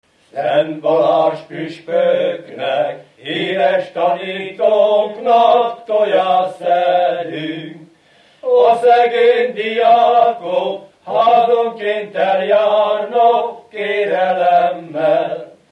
Felföld - Pozsony vm. - Felsőszeli
Műfaj: Balázsjárás
Stílus: 7. Régies kisambitusú dallamok